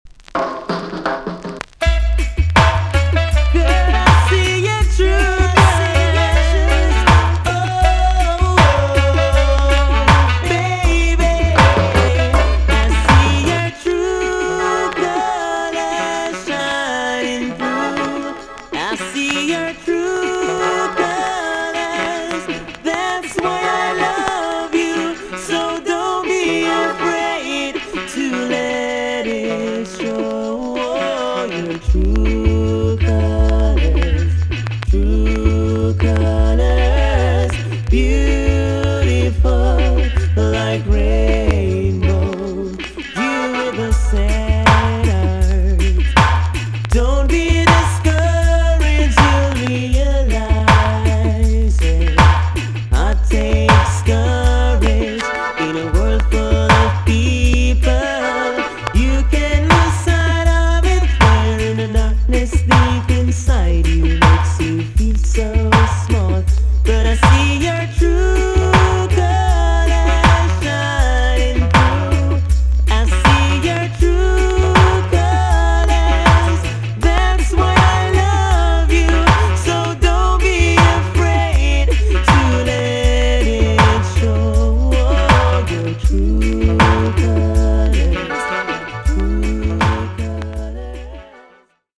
TOP >80'S 90'S DANCEHALL
VG+ 少し軽いチリノイズがあります。
HEAVY ROCK RIDDIM